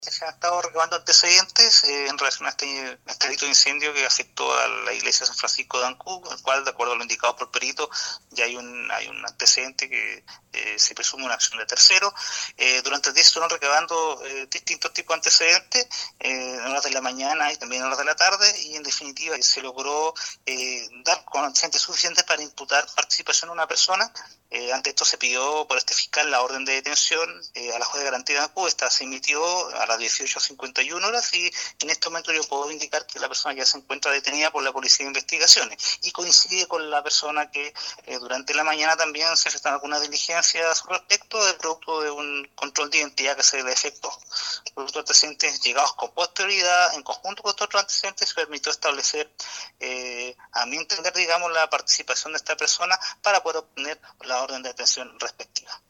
El fiscal de Ancud expresó que la orden de la aprehensión emanó desde el Juzgado de Garantía de la comuna.